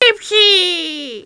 CLICK ON A TELETUBBIE TO HEAR THEM TALK!